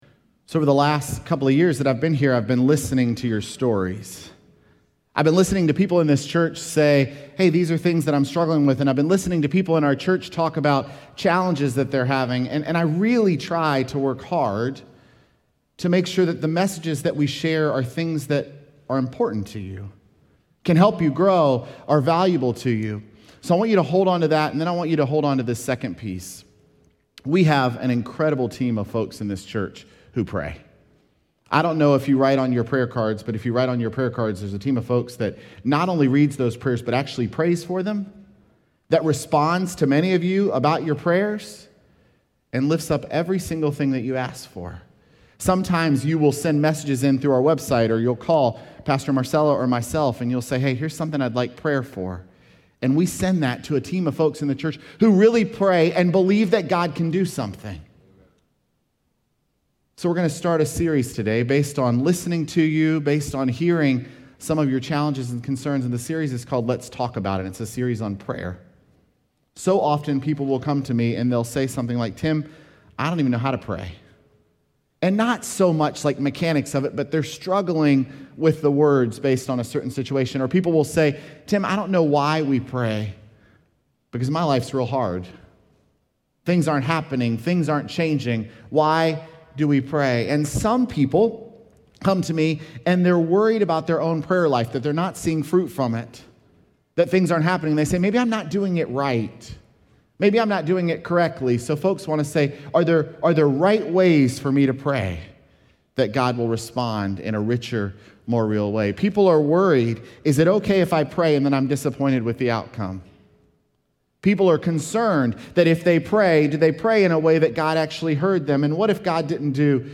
Sermons
Feb4SermonPodcast.mp3